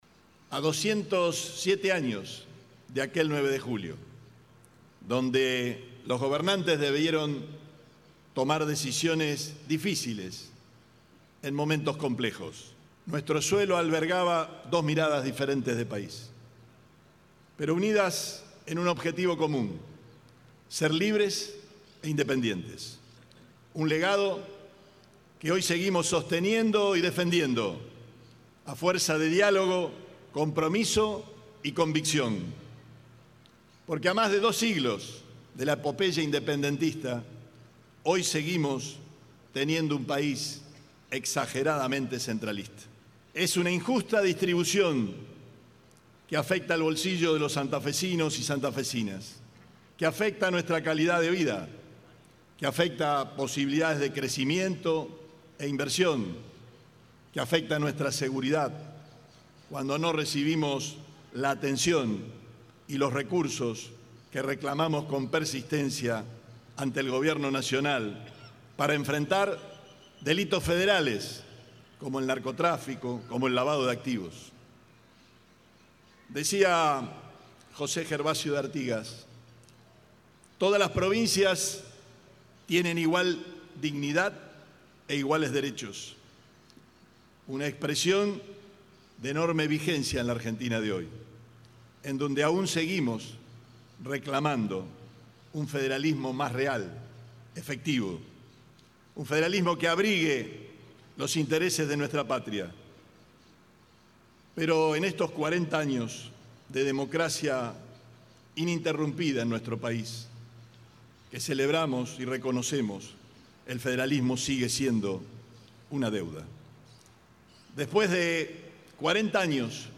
Declaraciones Perotti - primera parte